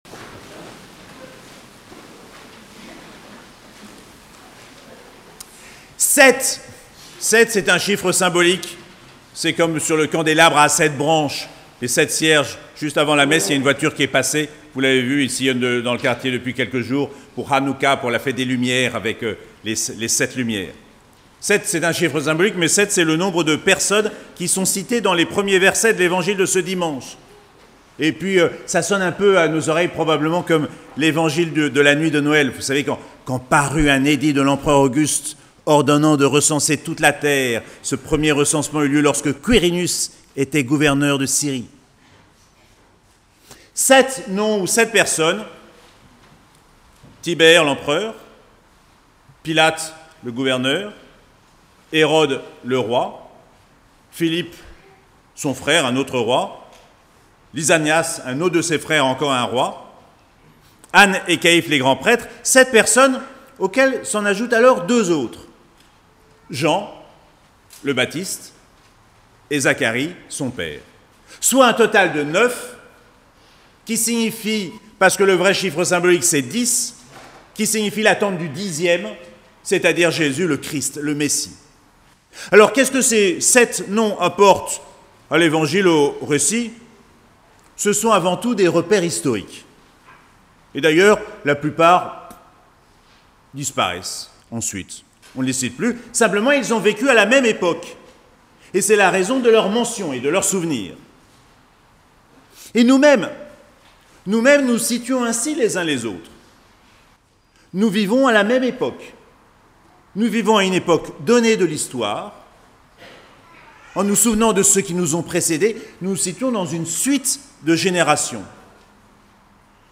2ème Dimanche de l'Avent - 5 décembre 2021